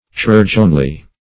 chirurgeonly - definition of chirurgeonly - synonyms, pronunciation, spelling from Free Dictionary Search Result for " chirurgeonly" : The Collaborative International Dictionary of English v.0.48: Chirurgeonly \Chi*rur"geon*ly\, adv.